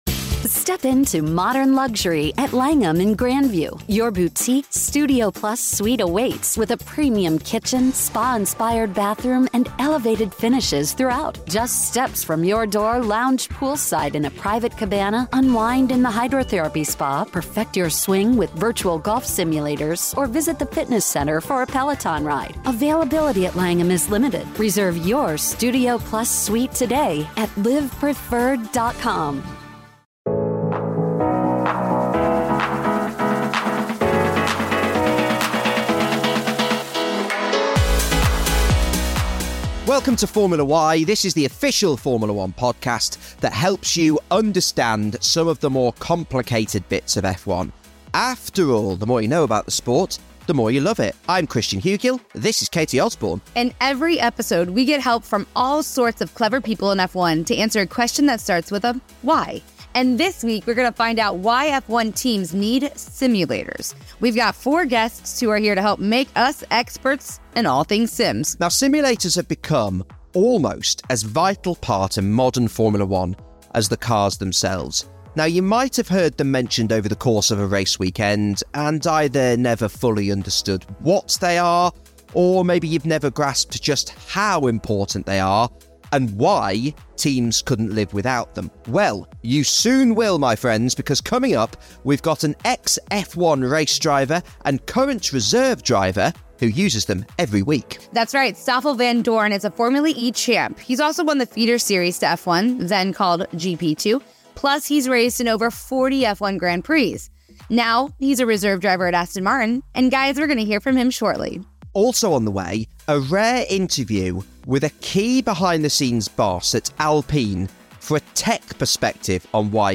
Stoffel Vandoorne, Aston Martin's reserve driver, took a break from a long day in the sim to tell us why they're so useful for racers.